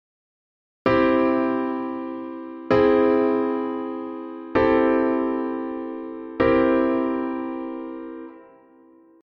・不安定で独特な響き
・不協和音ぽくて緊張感
🔽オーギュメントコードを含むコード進行
C→Caug→C6→C7
C-Caug-C6-C7.mp3